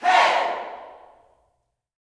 HEY  05.wav